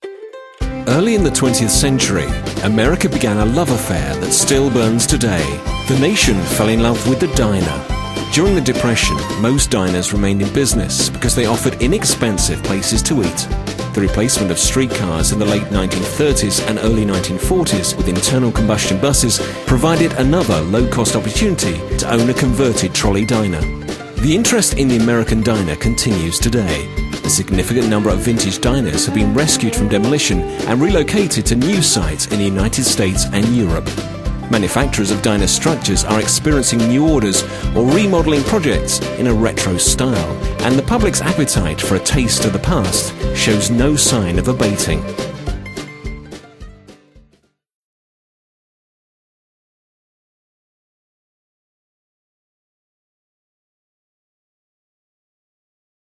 With twenty years of experience working in radio for the BBC and a rich, warm, cultured voice
Narration
01-American-Diner-Ad_.mp3